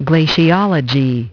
Transcription and pronunciation of the word "glaciology" in British and American variants.